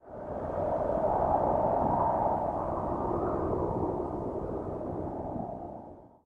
wind_medium.ogg